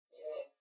mobs_rat.ogg